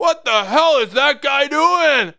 welder-seedeath2.mp3